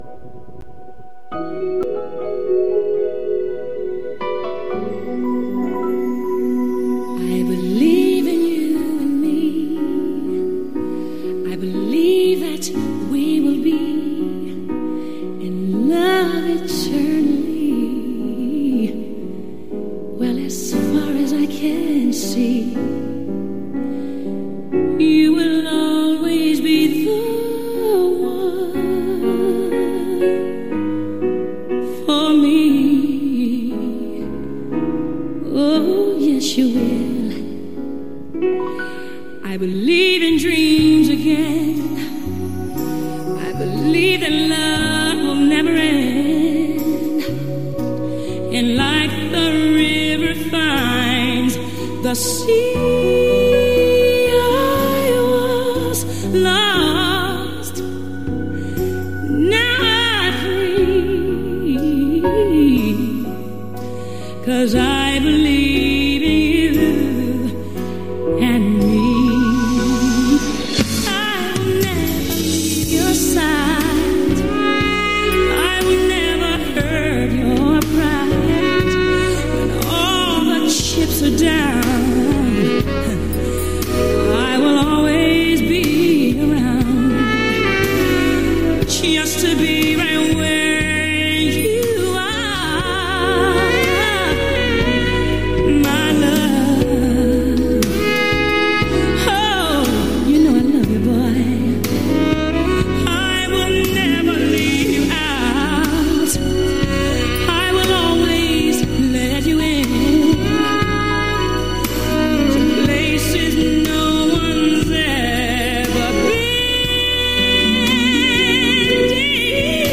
retro mixtape 2014_1209.mp3